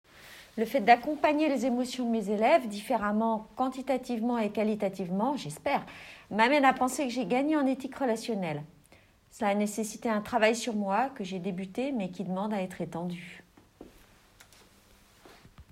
Un groupe de formateurs / formatrices ont participé à l'expérimentation. Voici leur témoignages